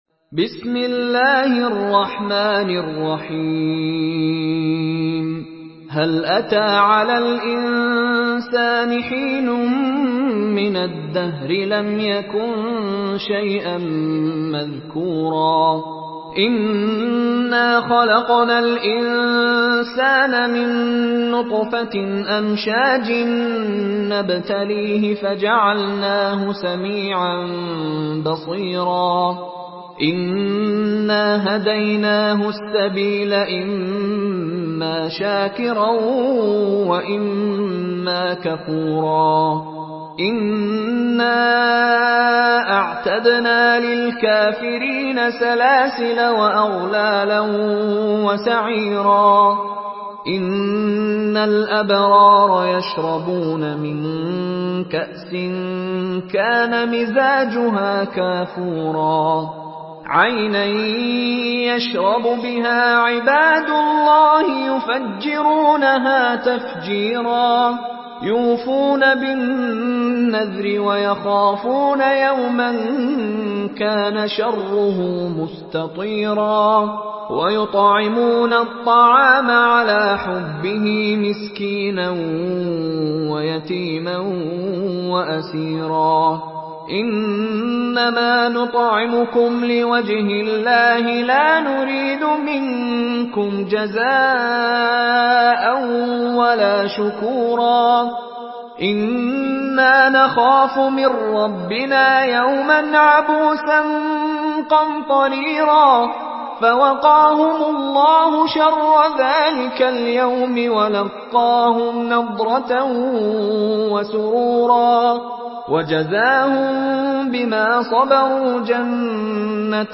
سورة الإنسان MP3 بصوت مشاري راشد العفاسي برواية حفص
مرتل حفص عن عاصم